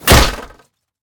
metal1.ogg